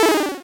Techmino/media/effect/chiptune/finesseError.ogg at beff0c9d991e89c7ce3d02b5f99a879a052d4d3e
finesseError.ogg